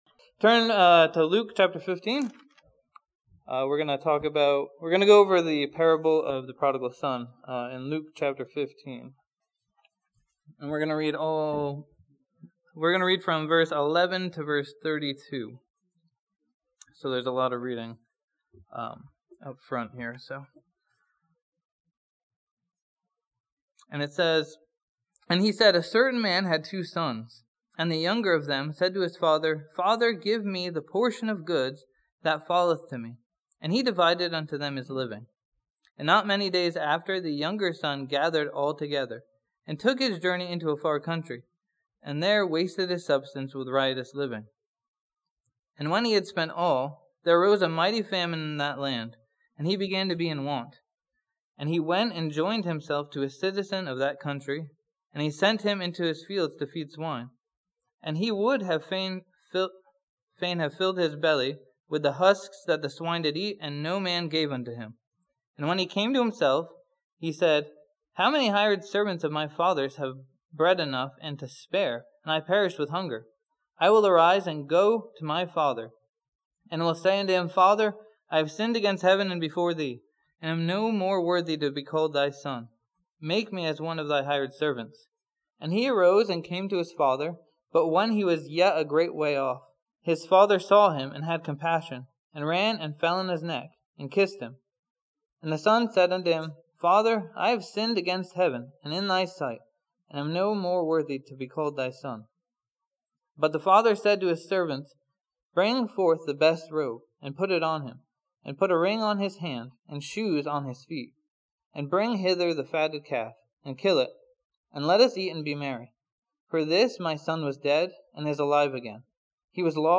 This sermon from Luke 15 and the prodigal son challenges the believer to remember what God has done for us.